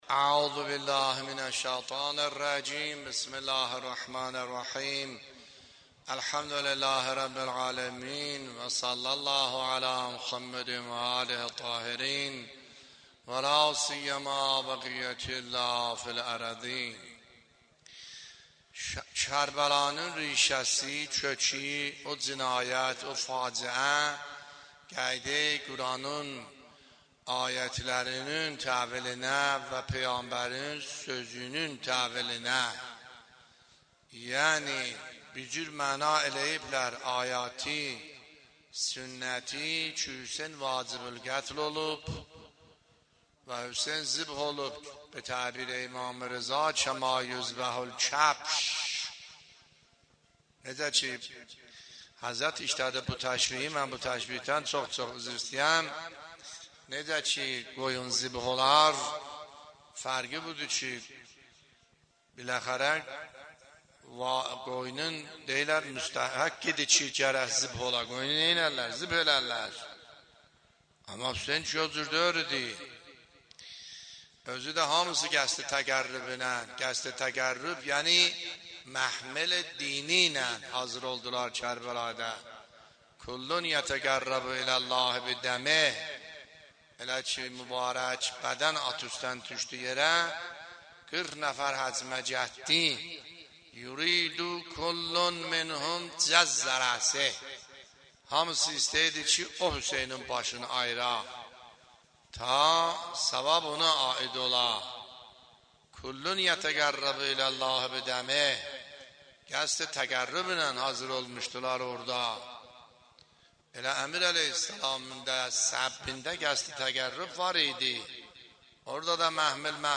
سخنرانی آیه الله سیدحسن عاملی فایل شماره ۳ - دهه اول محرم ۱۳۹۷